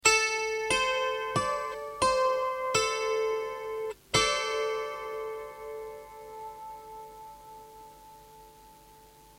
VIISIKIELINEN KANTELE